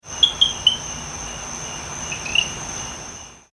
دانلود صدای قورباغه در برکه از ساعد نیوز با لینک مستقیم و کیفیت بالا
جلوه های صوتی